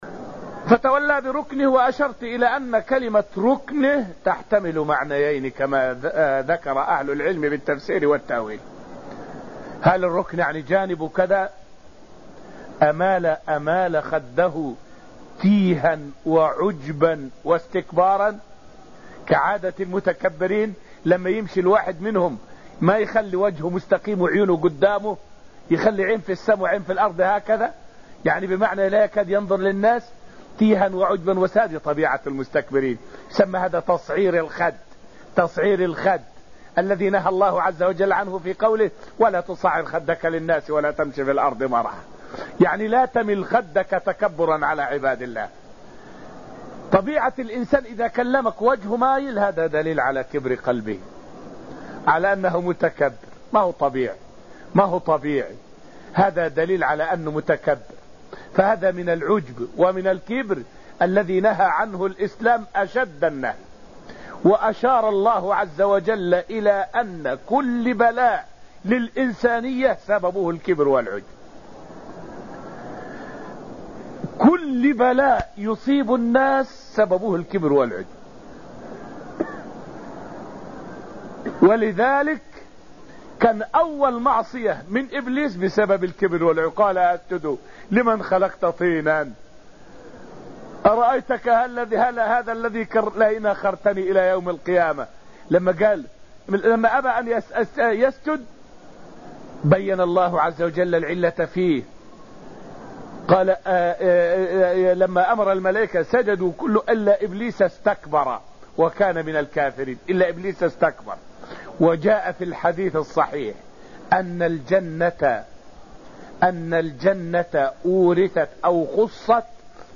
فائدة من الدرس الأول من دروس تفسير سورة الذاريات والتي ألقيت في المسجد النبوي الشريف حول أن الكِبر يحجب عن رؤية الحق ويبعث على جحود النعم.